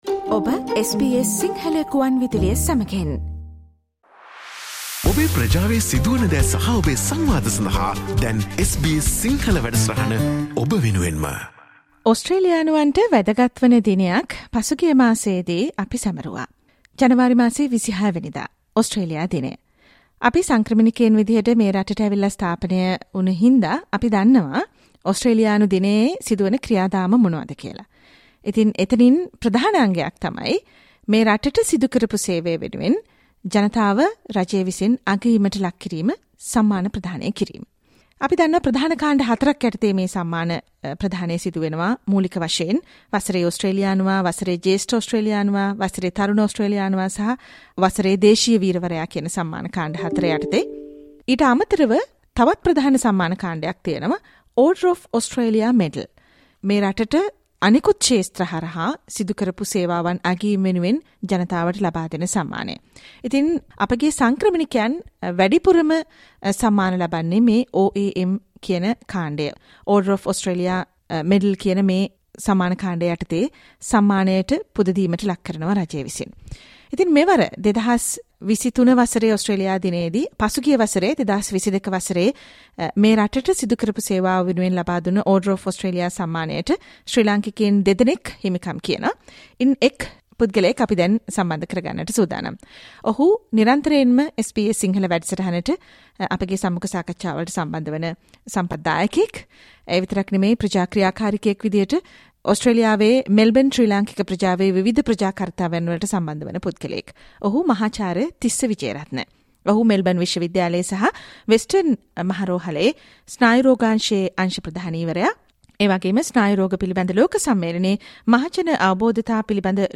SBS සිංහල සේවය සිදුකල සාකච්ඡාවට සවන්දෙන්න.